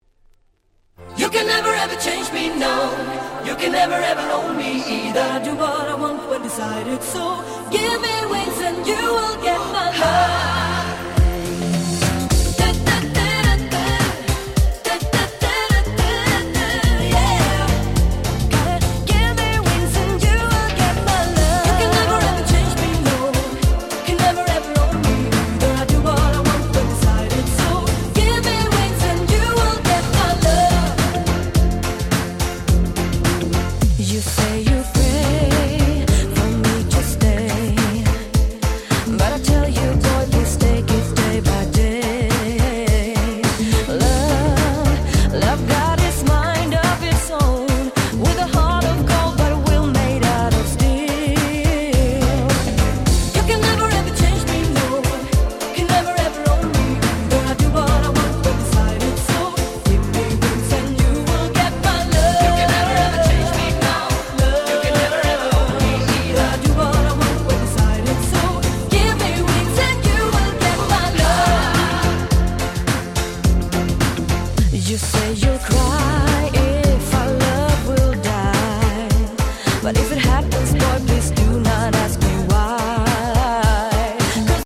96' Dance Pop Hit !!
Popでキャッチー！言う事無し！